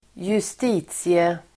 Uttal: [²just'i:tsie-]